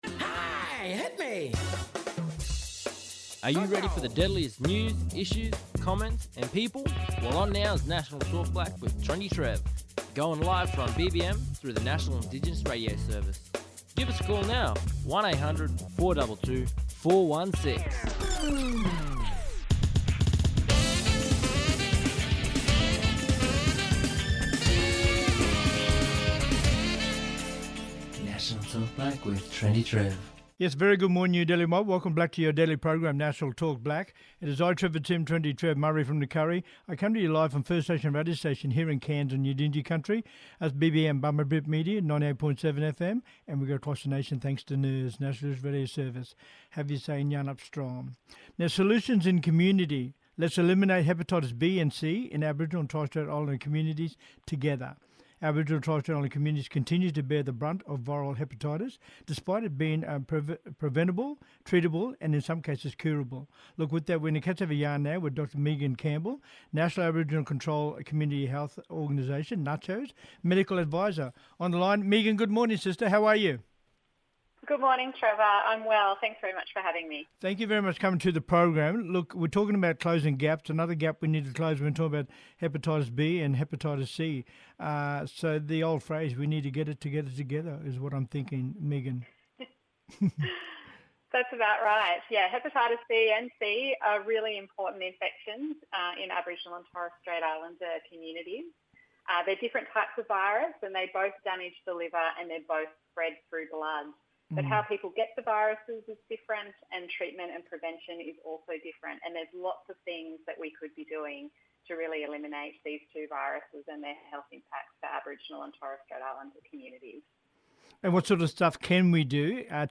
Special Guests Live today from the Remote/Indigenous Women’s Shelter Network Forum. Where organisations and delegates are gathering To acknowledge the Historic Milestone as the Network Gathers After 20 years.